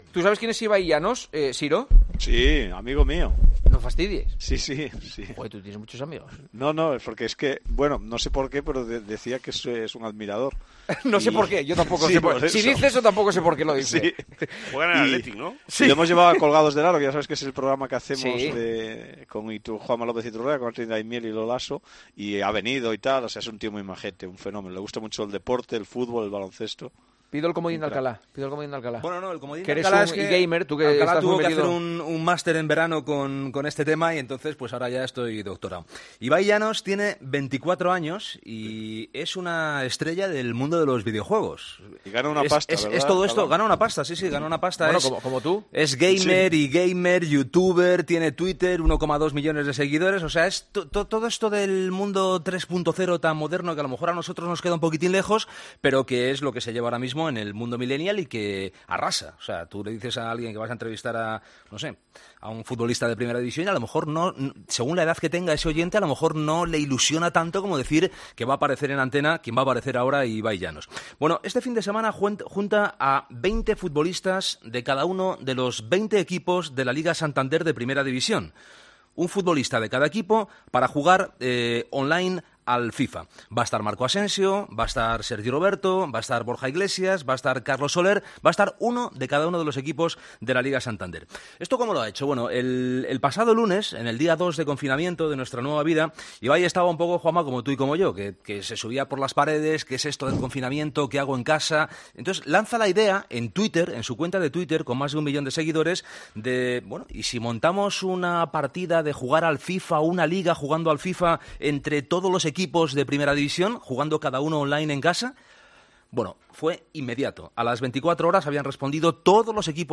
Hablamos con Ibai Llanos sobre el torneo benéfico de FIFA entre los futbolistas de LaLiga